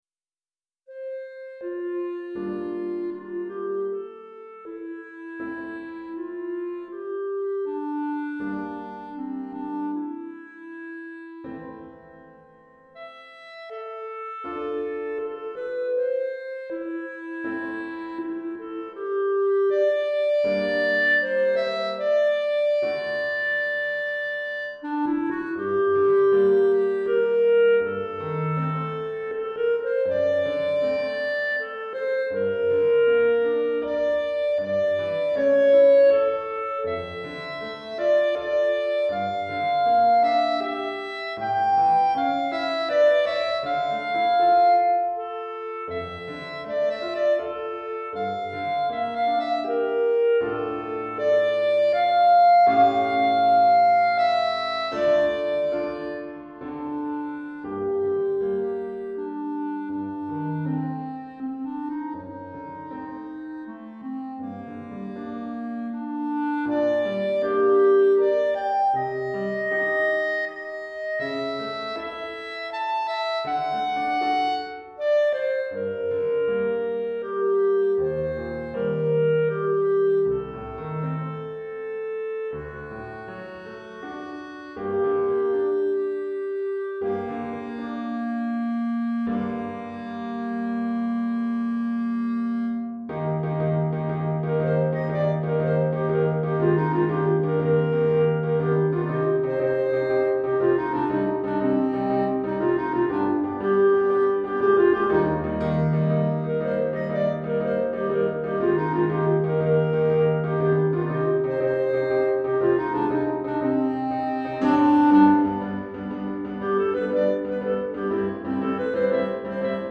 Pour clarinette et piano DEGRE DEBUT DE CYCLE 2